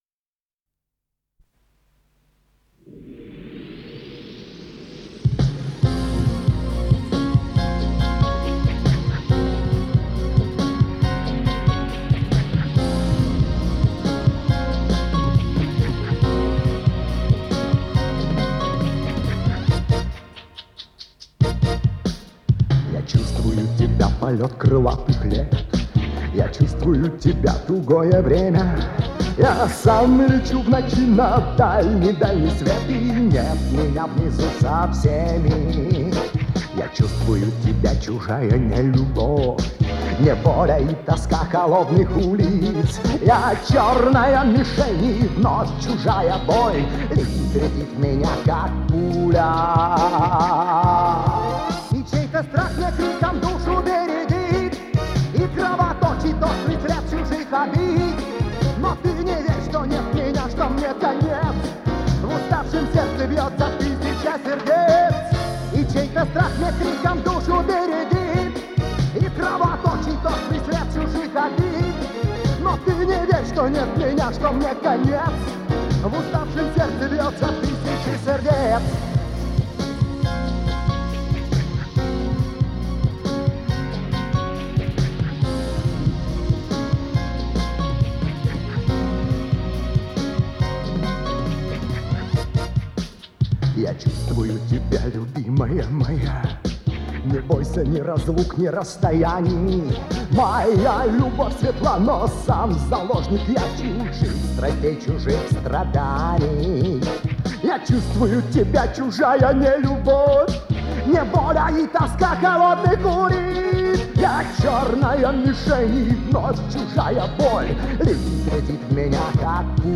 с профессиональной магнитной ленты
Скорость ленты38 см/с
МагнитофонМЭЗ-109М